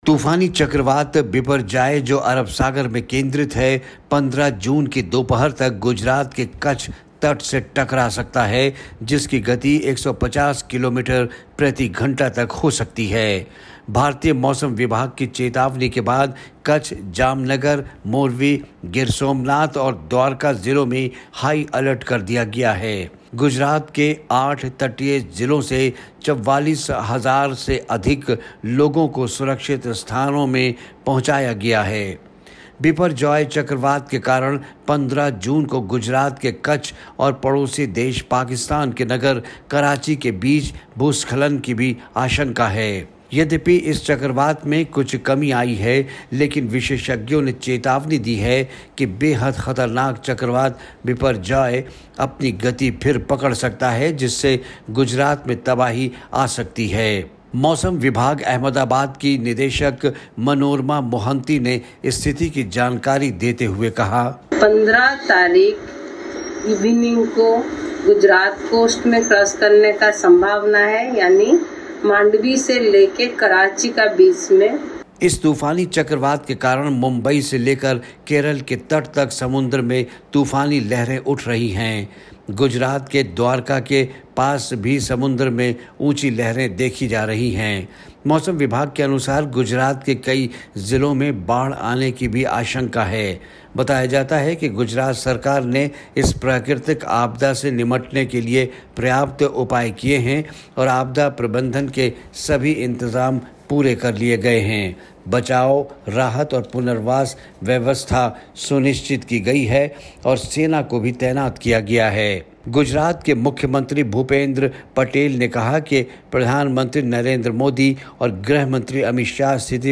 बिपरजॉय चक्रवात को लेकर गुजरात में रेड अलर्ट जारी, रिपोर्ट